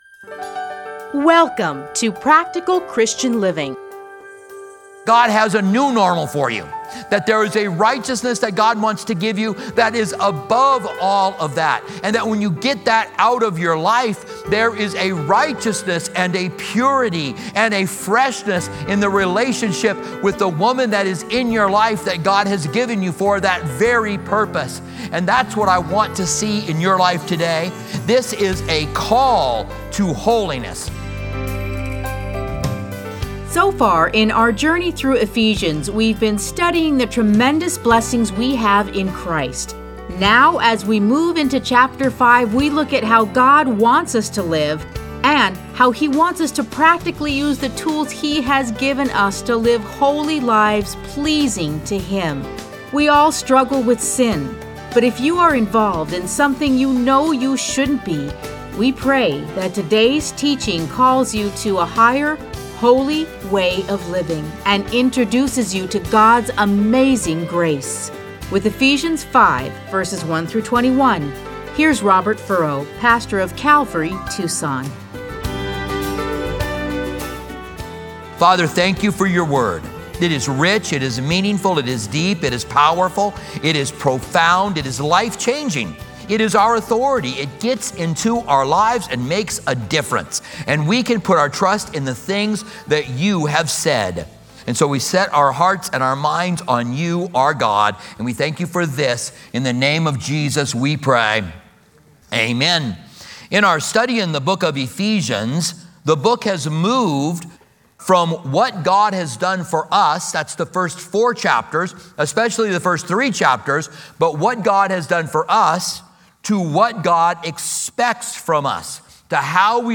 Listen to a teaching from Ephesians 5:1-21.